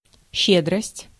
Ääntäminen
France: IPA: [la.ʒe.ne.ʁɔ.zi.te]